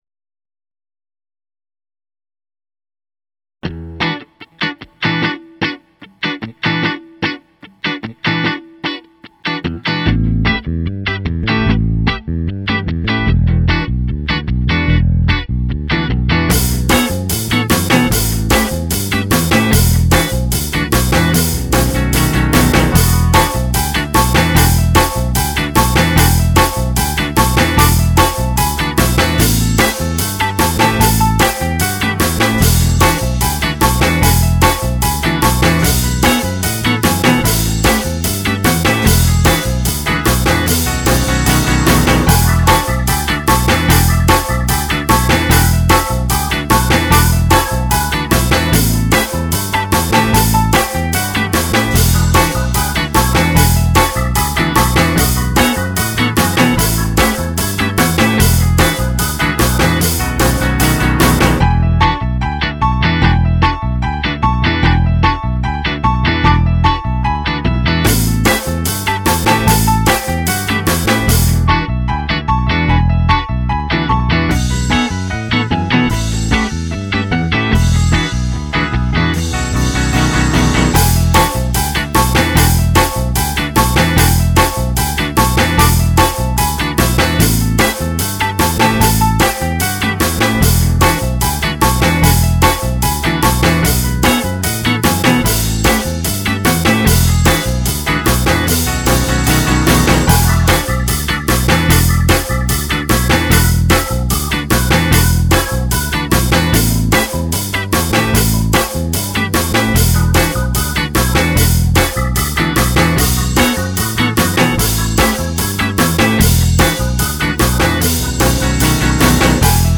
un misto di rock, jazz, stile anni 60! wow mi e' venuto proprio bene!
rockjazzpiano.mp3